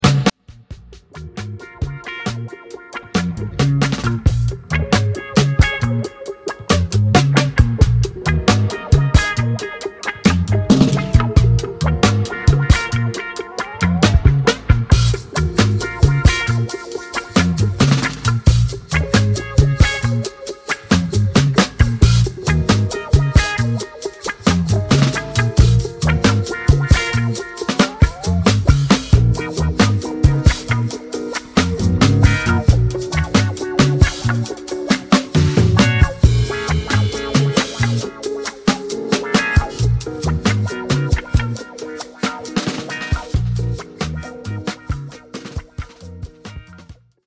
superior soulful instrumental hip hop